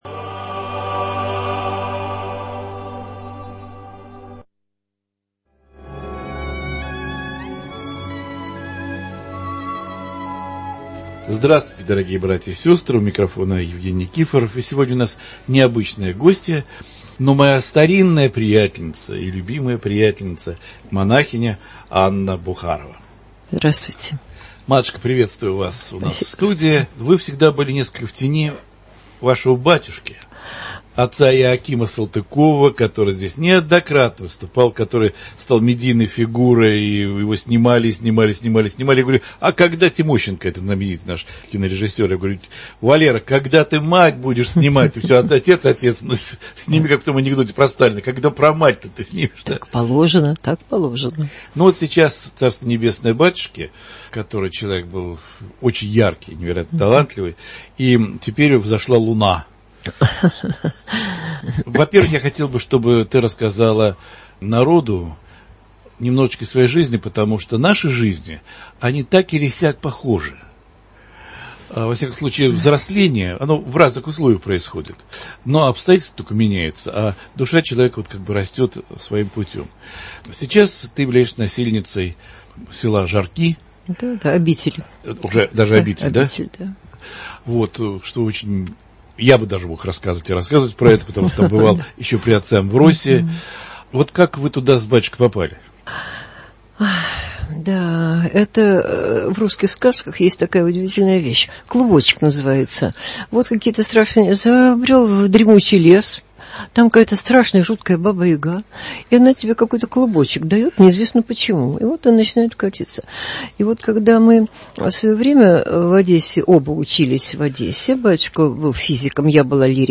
В студии радио Радонеж